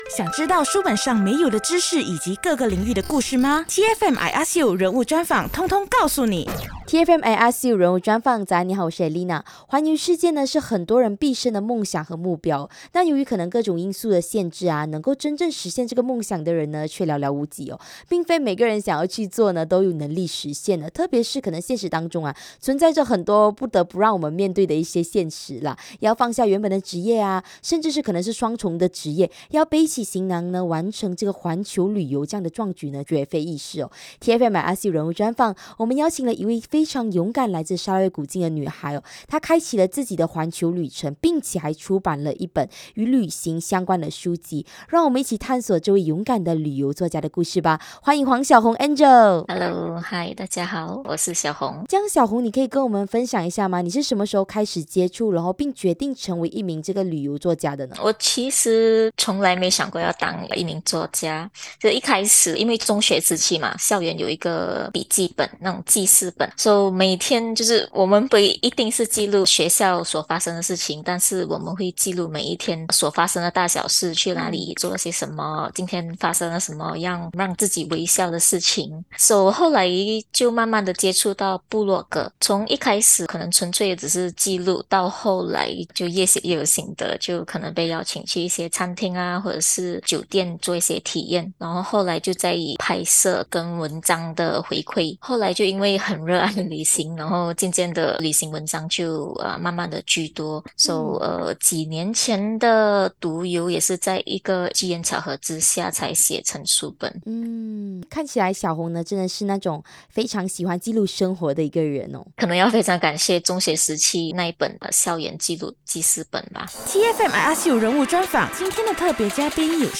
人物专访 旅游作家